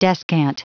Prononciation du mot descant en anglais (fichier audio)
Prononciation du mot : descant